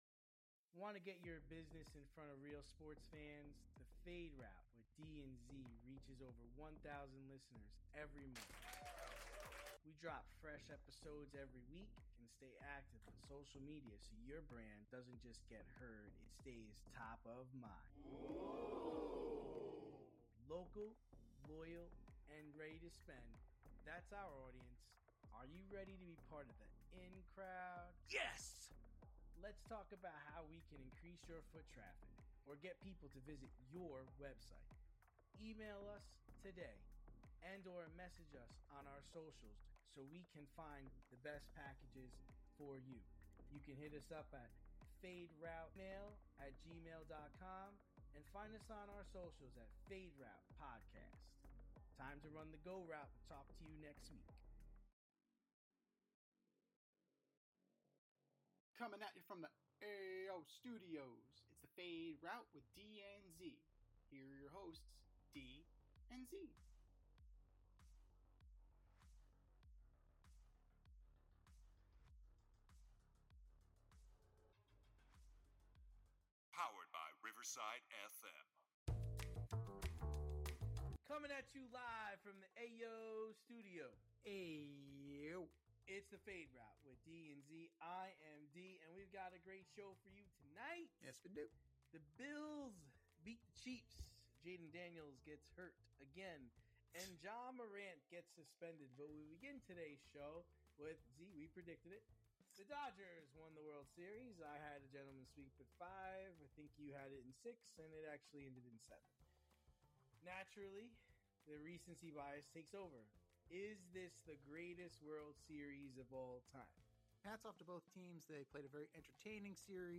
two veteran sports aficionados and lifelong friends